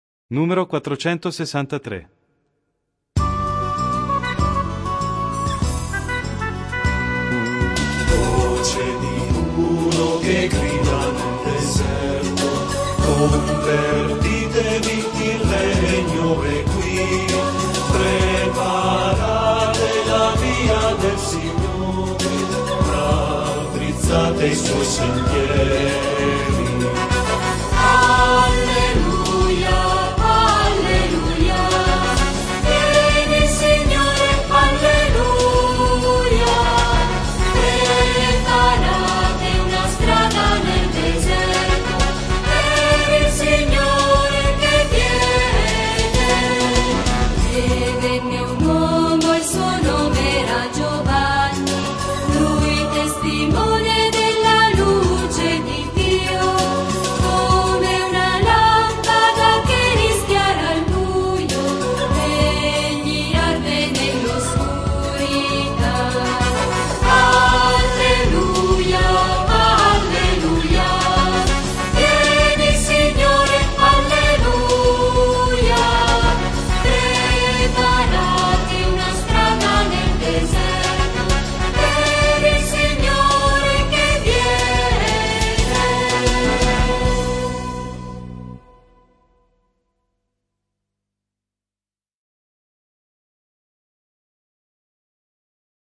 Il canto di avvento prepara, come in un cammino, l'avvicinarsi al Natale: ecco allora che i tempi musicali sono quasi tutti in 3/4 o 6/8, come a mimare quel passo che ci accompagna verso il mistero: canti di meditazione e di incontro con la Parola che salva: non ci sarà più il Gloria, ma daremo spazio al canto della misericordia di Dio, invocando Kyrie, eleison!